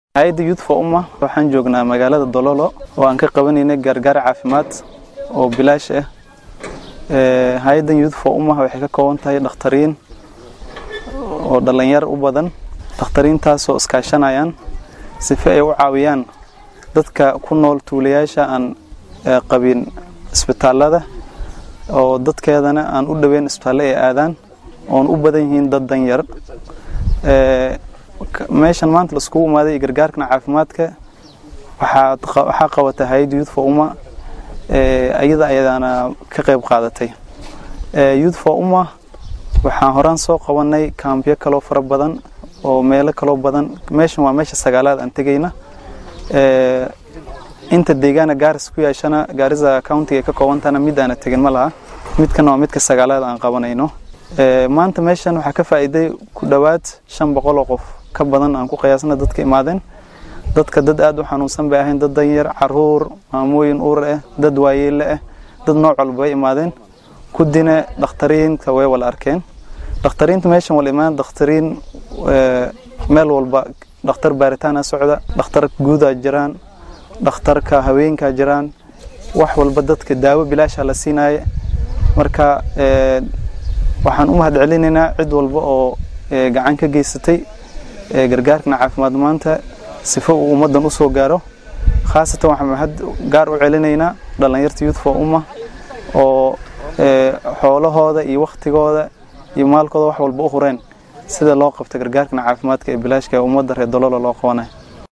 Dhallinyaro dhakhaatiir ah oo ka tirsan hay’adda Youth for Ummah ayaa daaweyn lacag la’aan ah u fidiyay shacabka ku nool tuulada Doloolo ee deegaan baarlamaneedka Balambala ee ismaamulka Garissa. Mid ka mid ah howlwadeennada hay’adda ayaa ka warbixiyay adeegyada caafimaad ee ay dadweynaha gaarsiiyeen.